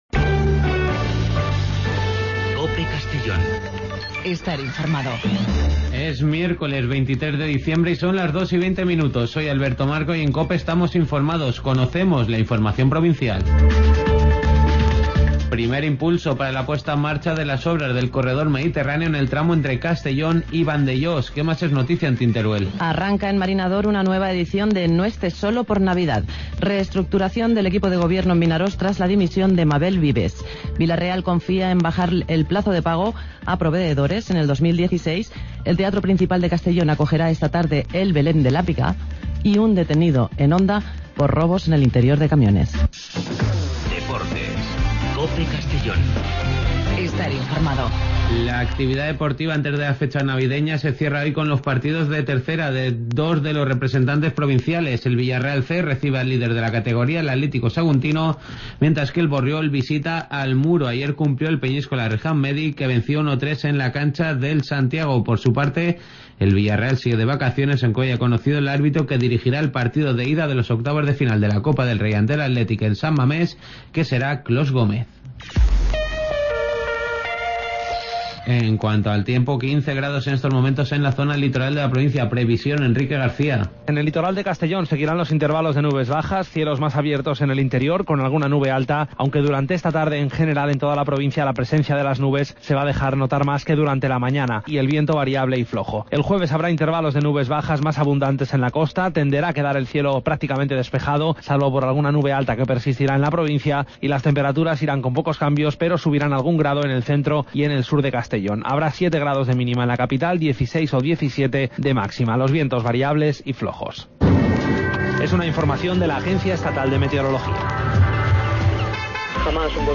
Espacio informativo a nivel provincial, con los servicios informativos de COPE en la provincia de Castellón. De lunes a viernes de 14:20 a 14:30 horas.